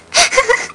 Giggle Sound Effect
Download a high-quality giggle sound effect.
giggle.mp3